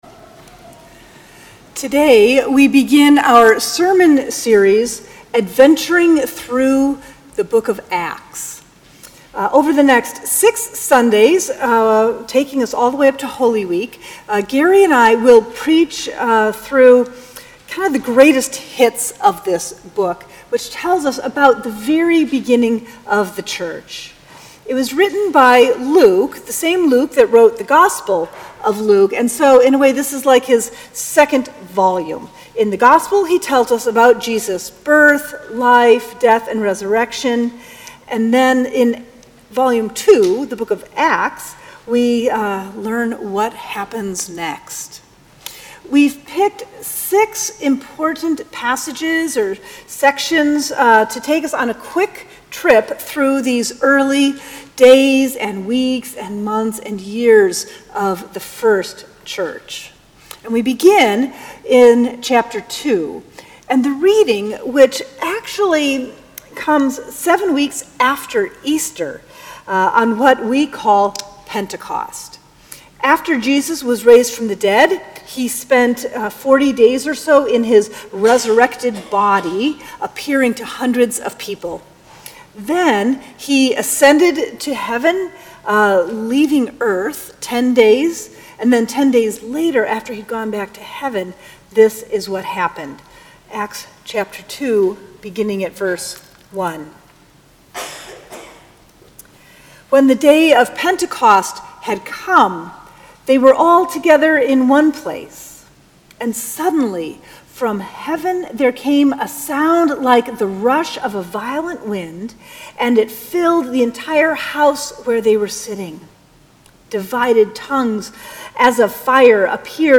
Sermons
March 3, 2019 Last Sunday after Epiphany